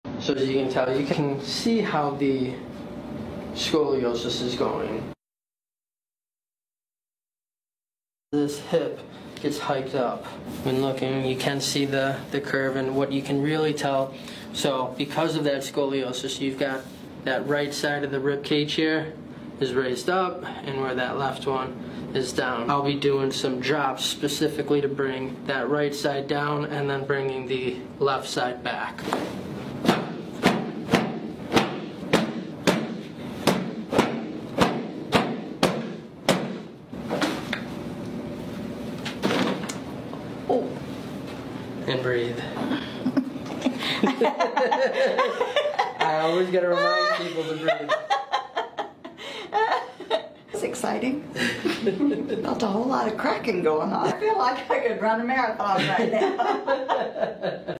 Extreme Scoliosis Back Cracking!